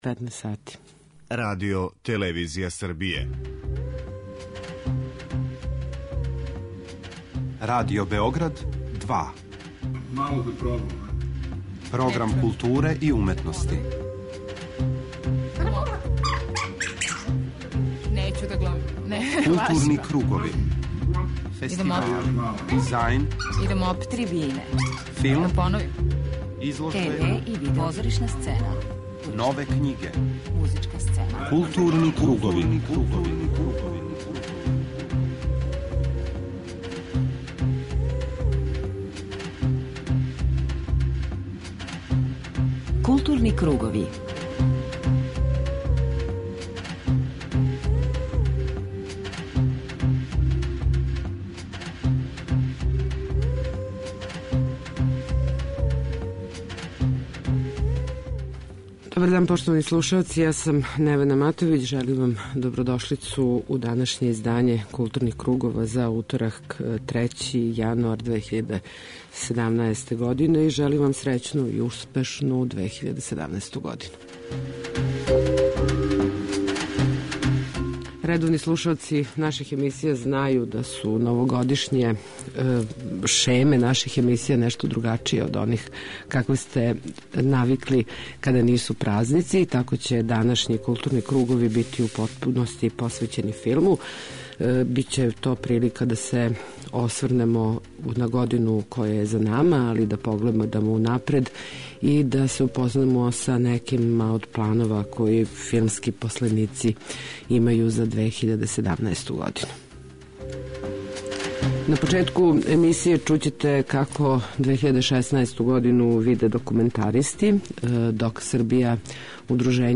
преузми : 41.12 MB Културни кругови Autor: Група аутора Централна културно-уметничка емисија Радио Београда 2. Како би што успешније повезивали информативну и аналитичку компоненту говора о култури у јединствену целину и редовно пратили ритам културних збивања, Кругови имају магазински карактер.